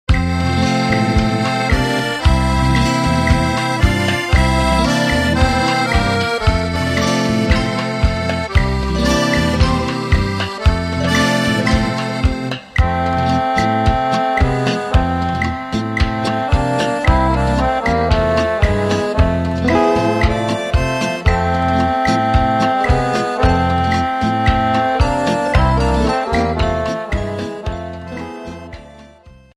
Ballabili Sudamericani
Beguine